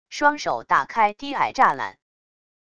双手打开低矮栅栏wav音频